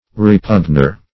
Repugner \Re*pugn"er\ (r?-p?n"?r), n. One who repugns.